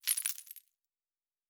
Objects Small 05.wav